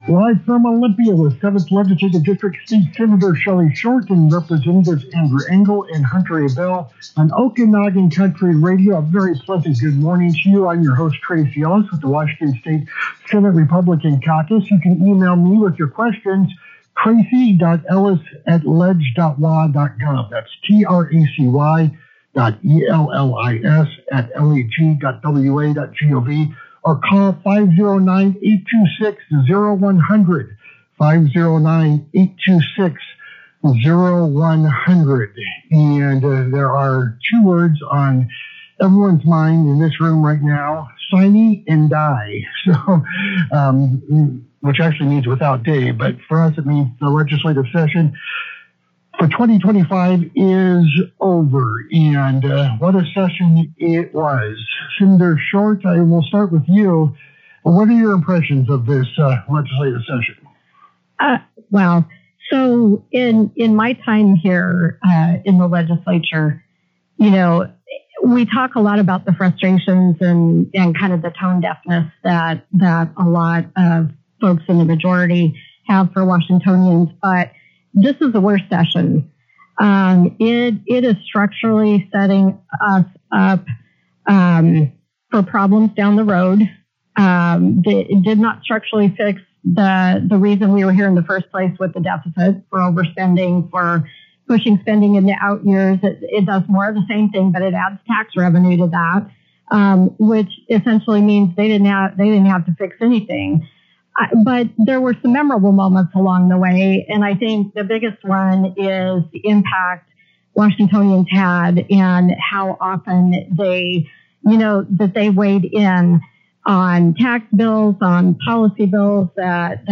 Sen. Shelly Short and Reps. Andrew Engell and Hunter Abell discuss the conclusion of the legislative session, offering insights on rural priorities, tax policies, wolf management, and education funding during their weekly interview on Okanogan Country Radio.